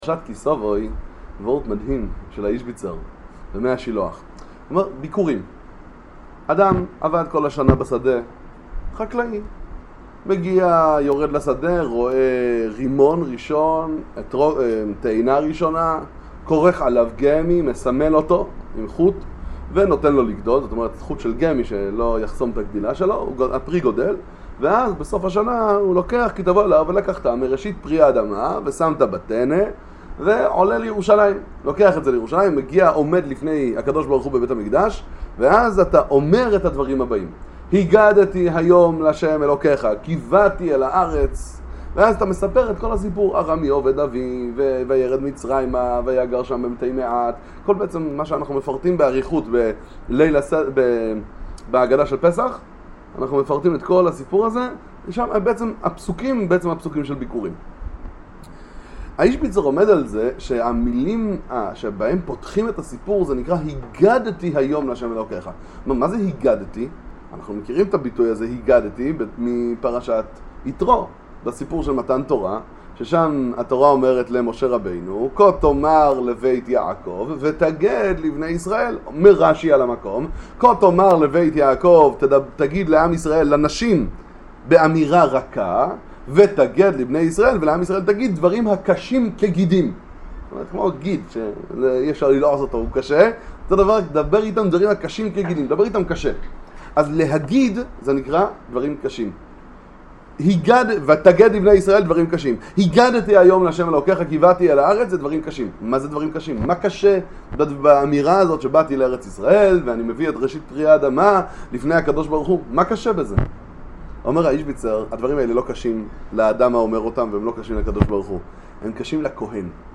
מה הדברים שקשה לכהן בבית המקדש לשמוע? שיעור קצר בספר מי השילוח פרשת כי תבוא